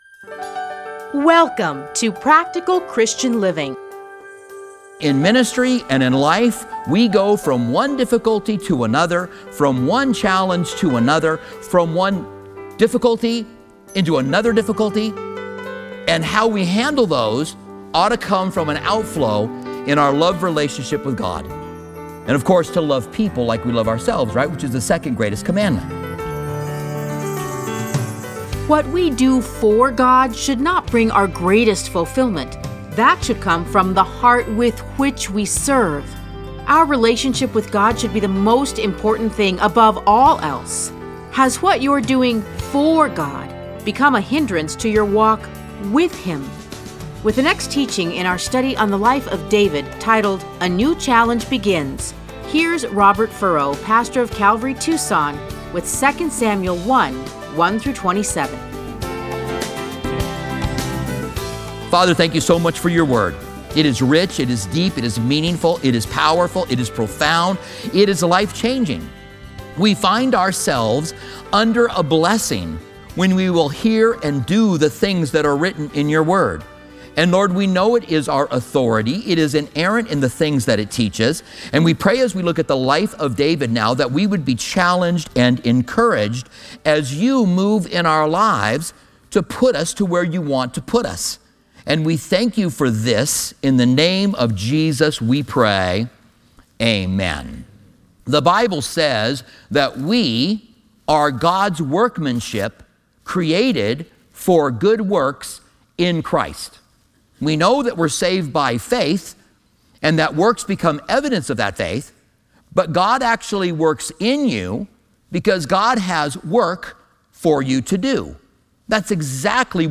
Listen to a teaching from 2 Samuel 1:1-27.